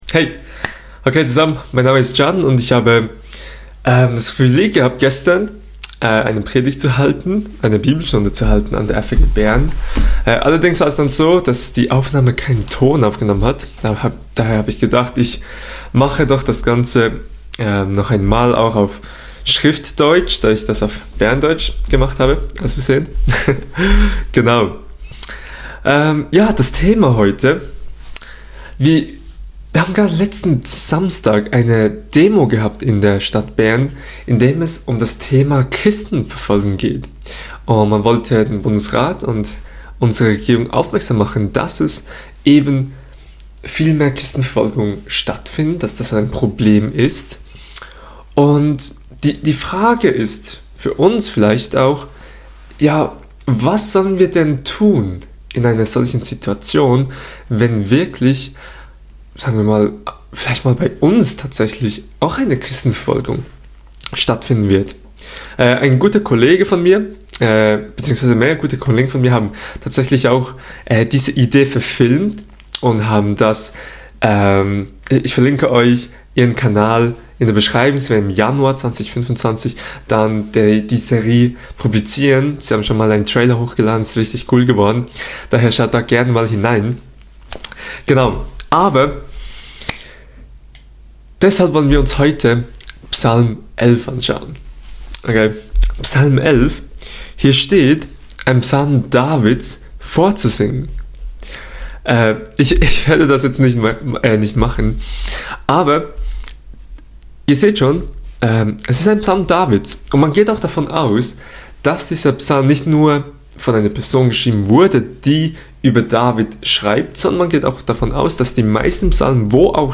Was machen wir, wenn wir als Christen vor Herausforderungen stehen aufgrund unseres Glaubens? Wir schauen uns diese Frage anhand Psalm 11 an. Kategorie: Bibelstunde Prediger